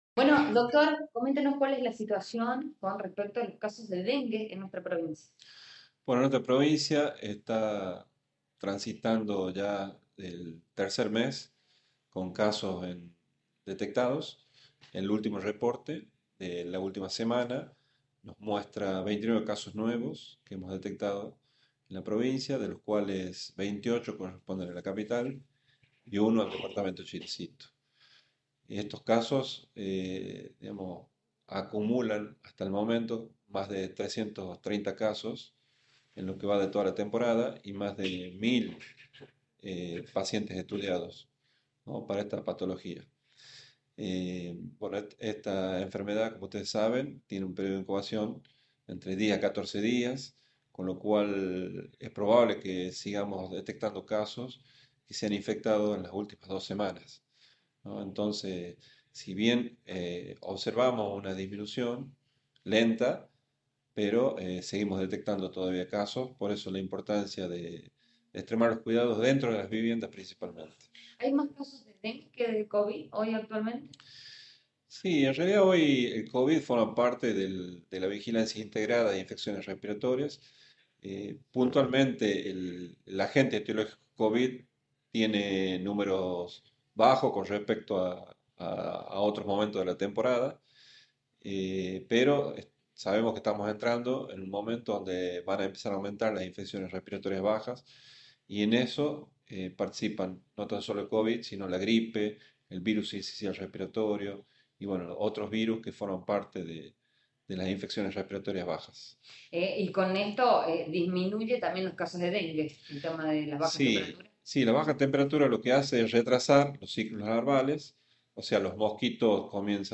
El titular de Epidemiología, Eduardo Bazán, informó que «de los nuevos contagios solo uno es de Chilecito y el resto es de Capital».